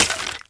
sounds / mob / bogged / hurt3.ogg
hurt3.ogg